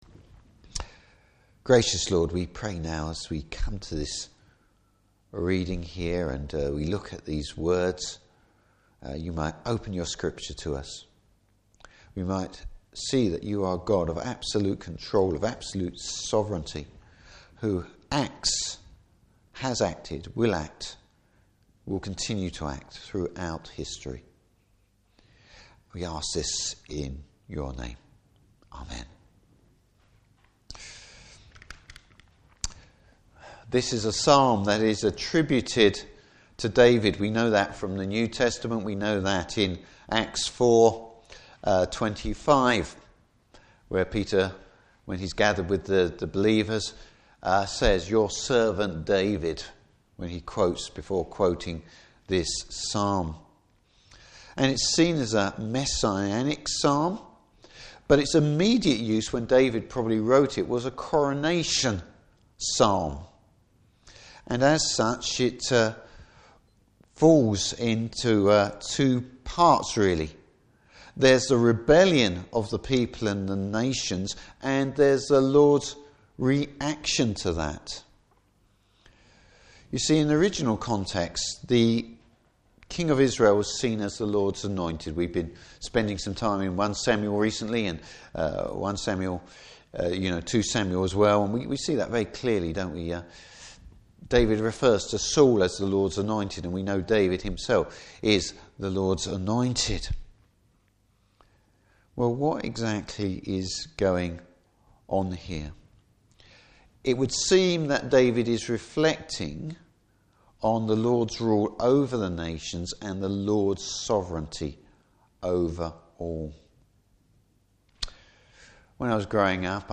Service Type: Morning Service Christ’s power and authority!